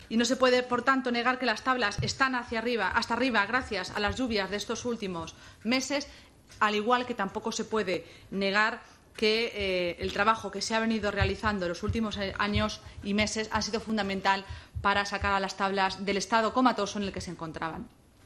Cortes de audio de la rueda de prensa
Cristina-Maestre-1.mp3